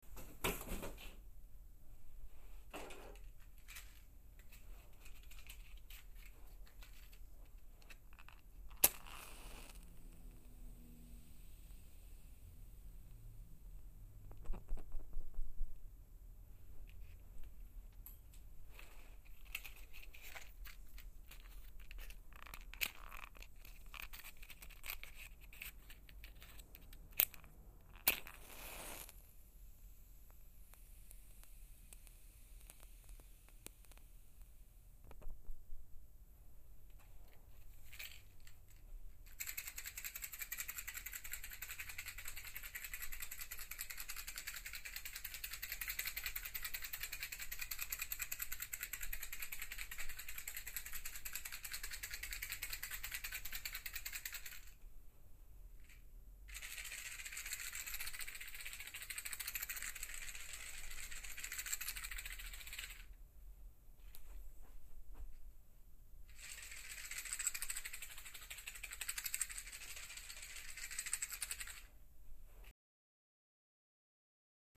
Grabación con dummy.
Se incluye la misma grabación de una caja de cerillas girando y moviéndose a nuestro alrededor en formato mono, estéreo y holofónico para apreciar mejor el efecto.
Aquí tenemos un ejemplo realmente sorprendente del efecto conseguido mediante esta técnica de grabación., en comparación con el mono y estéreo.
Caja de cerillas - holofónico
Cerillas_Holofonico.mp3